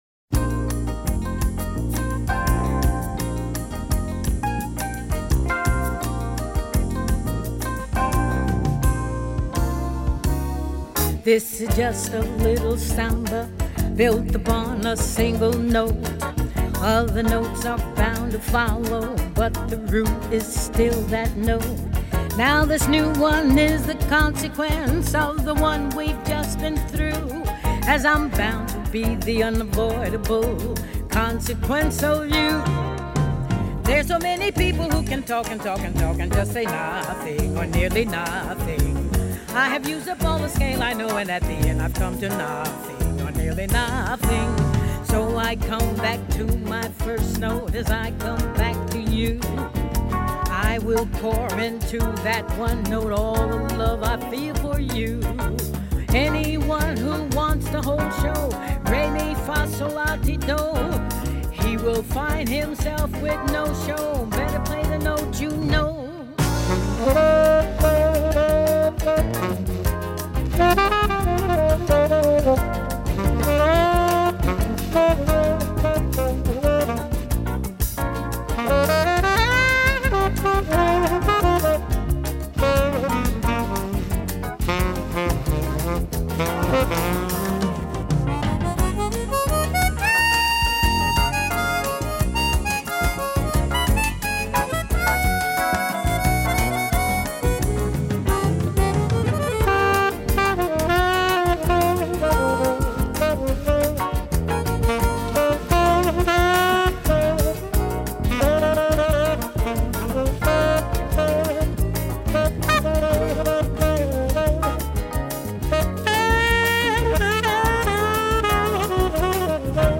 sunny interpretation
sax
trumpet
harmonica
guitar